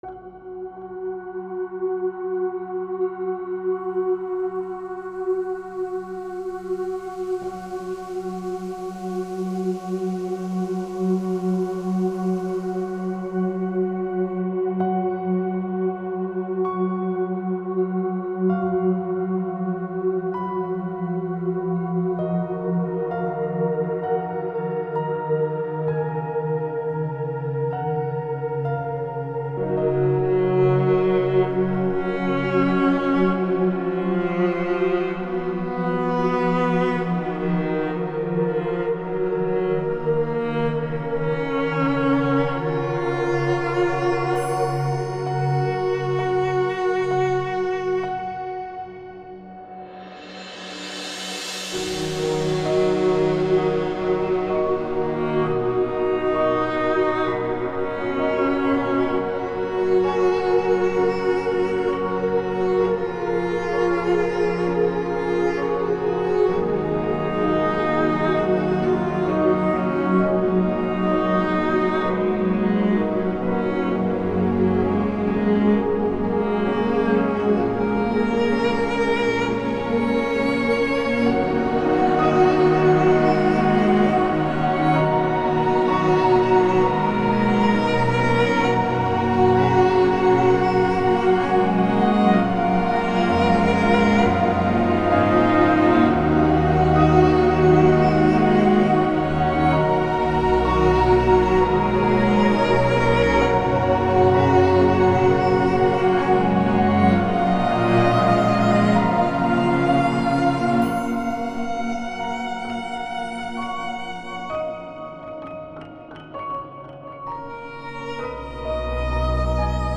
This is a mix of ambience and orchestra to create a song about intimacy.
orchestra raum reverb nada pads noire piano metals strings choirs rainstick intimate soothing peaceful emotional
Ambient and soft, this song tells a story of love and intimacy.